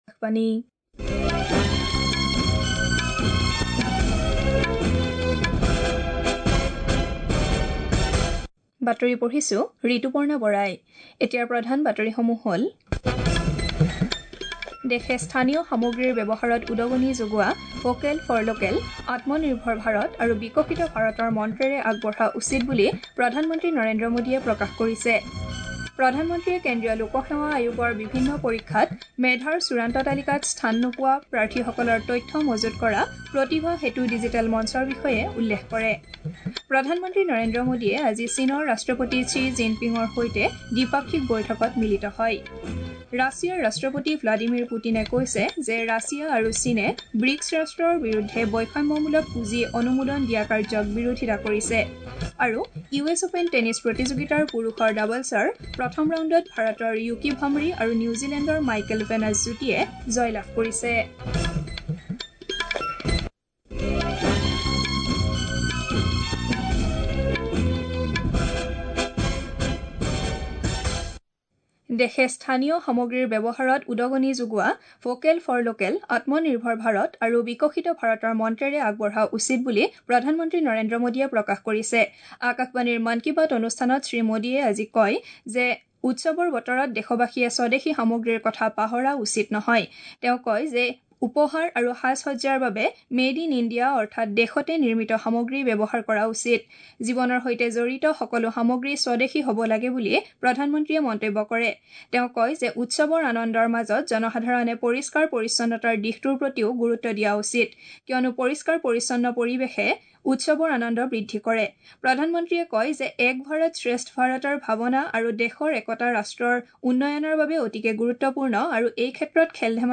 Play Audio Morning News